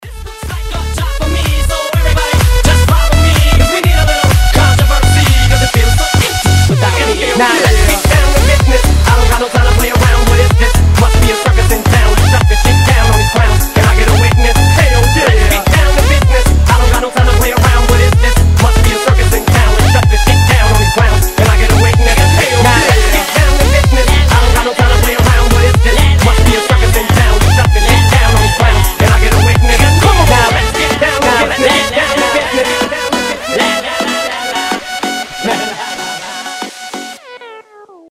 • Качество: 320, Stereo
громкие
качающие
progressive house
речитатив
Mashup
ремиксы